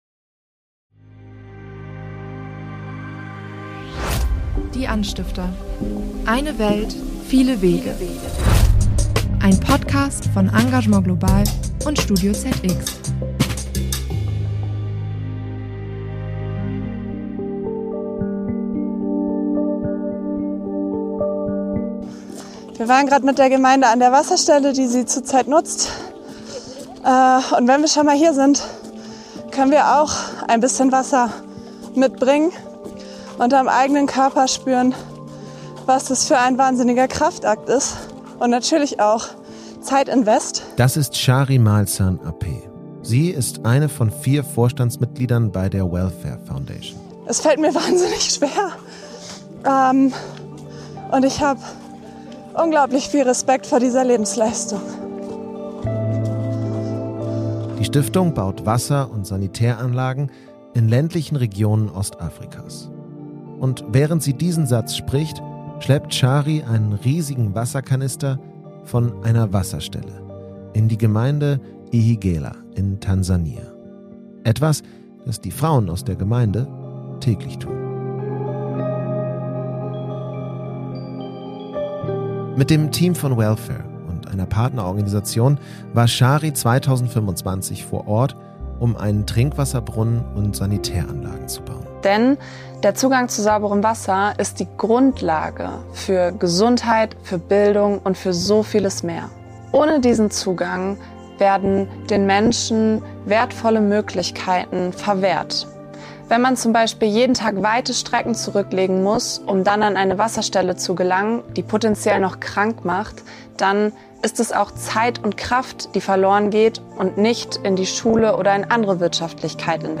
Das und mehr erklärt Neven Subotić in dieser Podcastfolge.